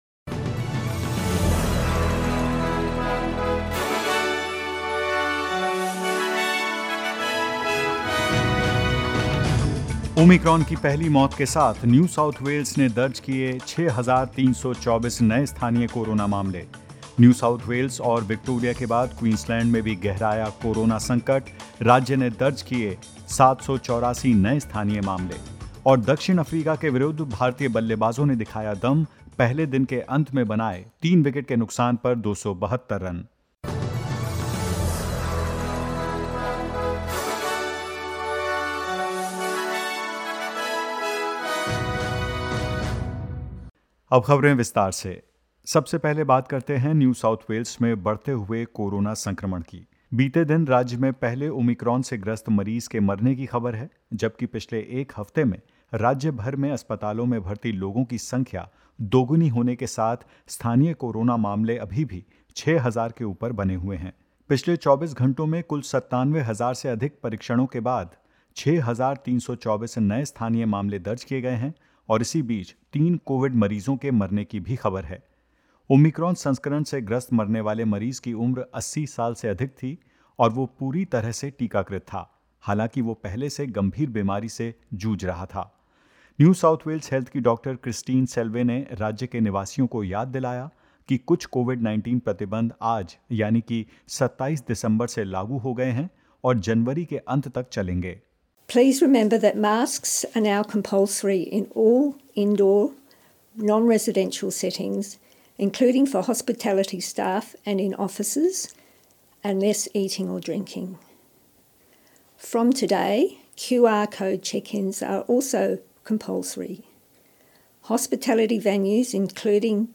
In this latest SBS Hindi news bulletin: Another 995 people incorrectly contacted about negative COVID-19 test results in Sydney; The Boxing Day Test and entire Ashes series is on alert after four non-playing members of England's touring group test positive to COVID-19 and more.